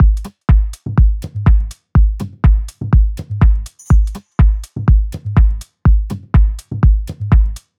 • house - techno beat passage 123bpm - Gm - 123.wav
A loop that can help you boost your production workflow, nicely arranged electronic percussion, ready to utilize and royalty free.